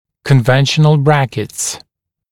[kən’venʃənl ‘brækɪts][кэн’вэншэнл ‘брэкитс]обычные брекеты (лигатурные)